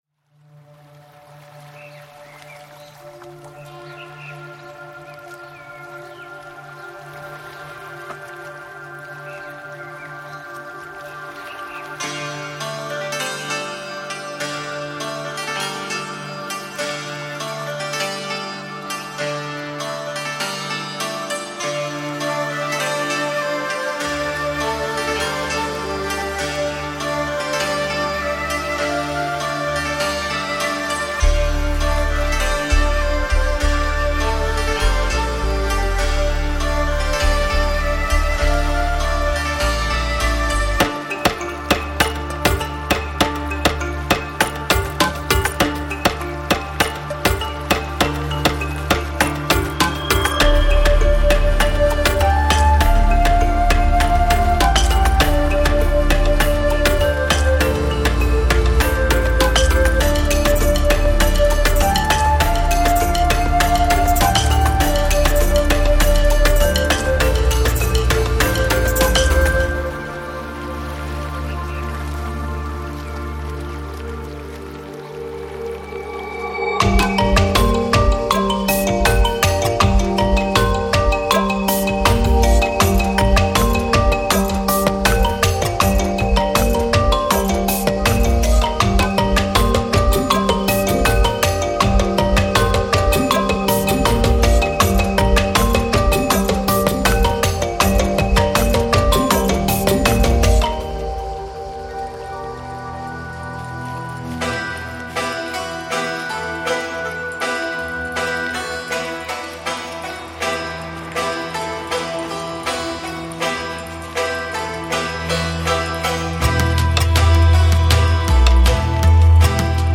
其中包含222个令人舒缓的循环和示
所有这些声音将为您的制作增添深度感和丰富感。
详细地，此包包括150个循环，范围从80到10OBPM，72个单发镜头和2个电池套件。
.031个效果和现场录音